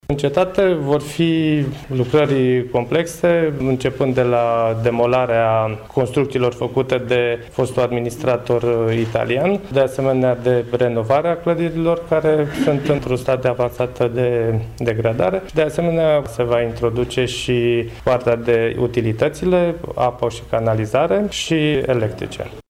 Viceprimarul din Râșnov, Liviu Butnariu: